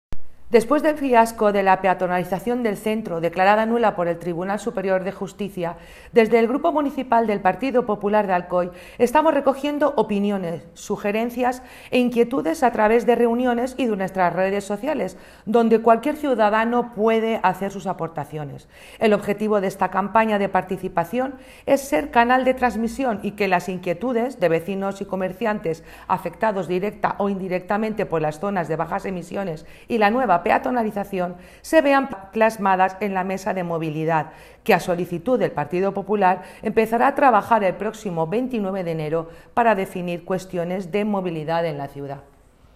corte-amalia-participacion.m4a